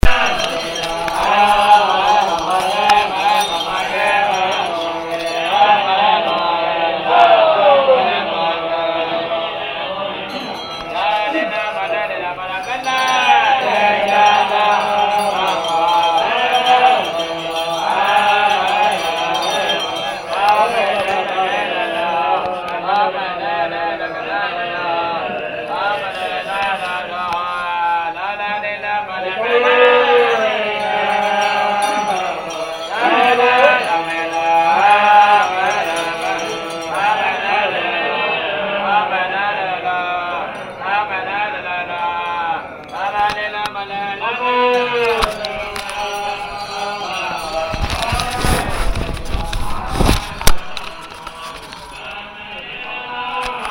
Ci-joints ma guide en train de tourner les moulins a priere (toujours dans le sens des aiguilles d'une montre; ces cylindres en bronze sont remplis de prieres), ainsi qu'une lecon de moines bouddhistes en live!
Lecons des moines bouddhistes.MP3